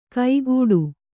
♪ kaigūḍu